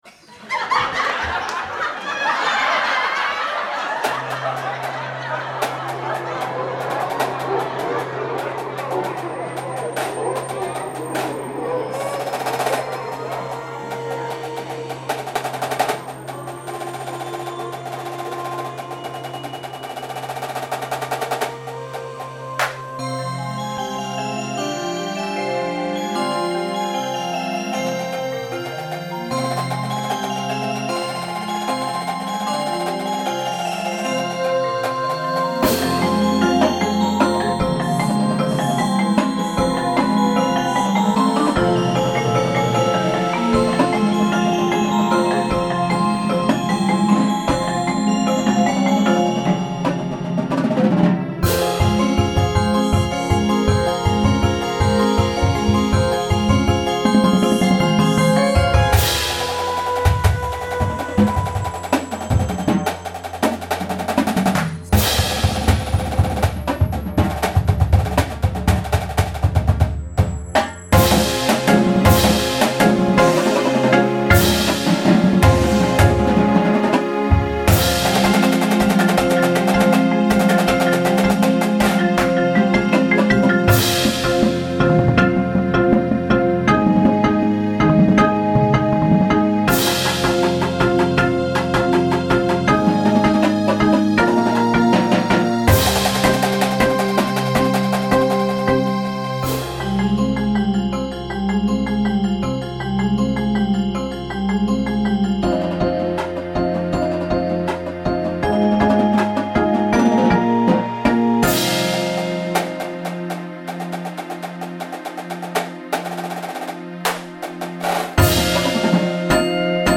• Bells
• Xylophone
• 1 Vibraphone (2 Vibe option)
• 1 Marimba (2 Marimba option)
• 1 Synthesizer (2 synth option)
• Snareline
• Tenors (5 or 6 drum options)
• Bassline (4 or 5 drum options)